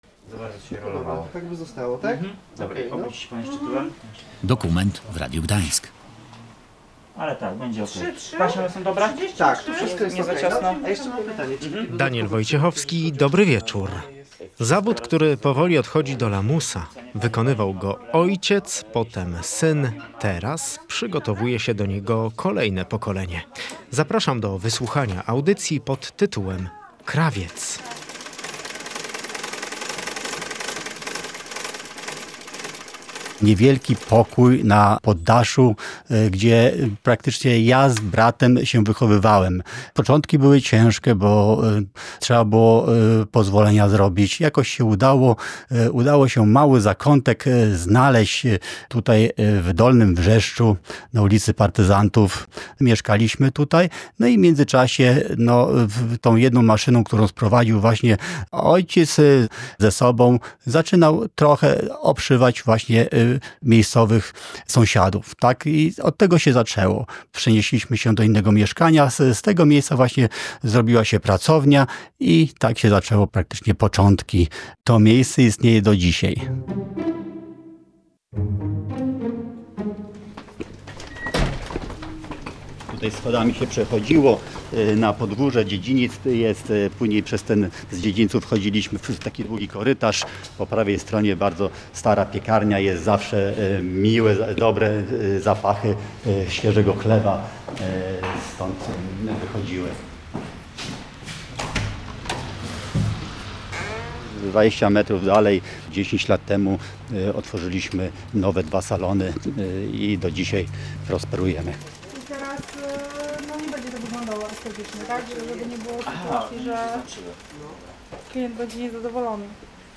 Zawód, który powoli odchodzi do lamusa. Posłuchaj dokumentu „Krawiec”
Zapraszamy do wysłuchania audycji dokumentalnej „Krawiec”.